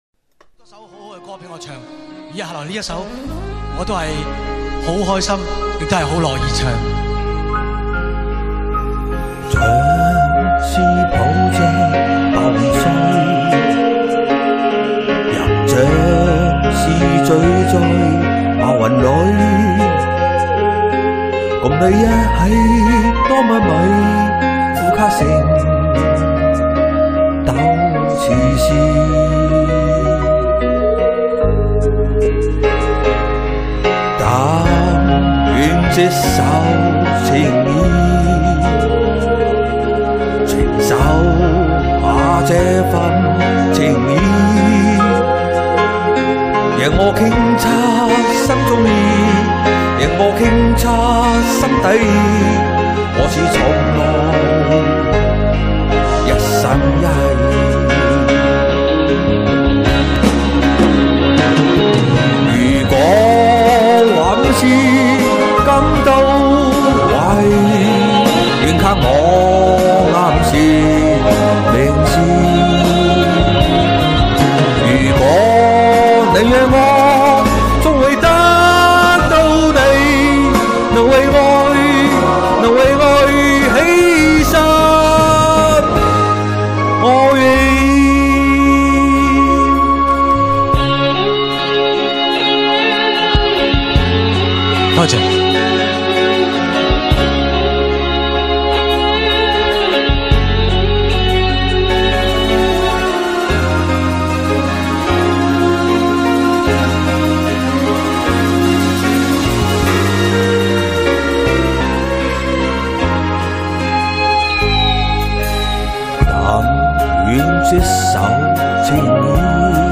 • 深情演唱，好听。